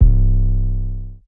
Scram808_YC.wav